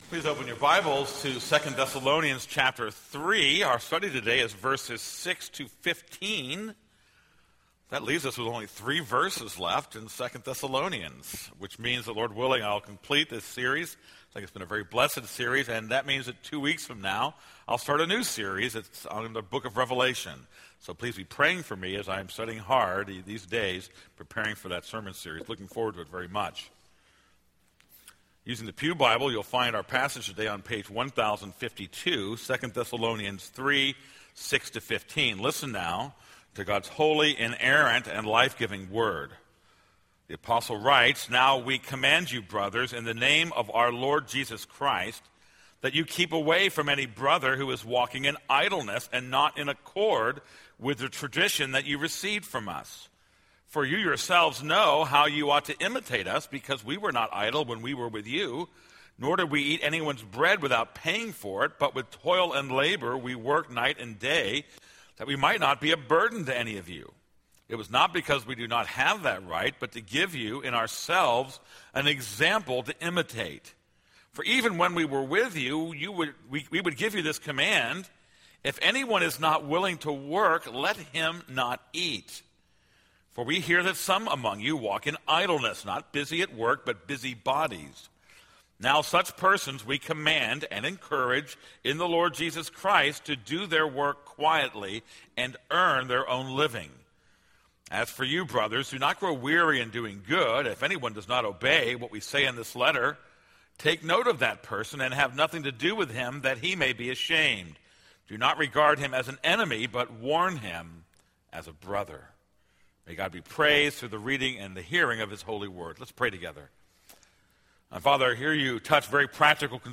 This is a sermon on 2 Thessalonians 3:6-15.